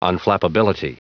Prononciation du mot unflappability en anglais (fichier audio)
Prononciation du mot : unflappability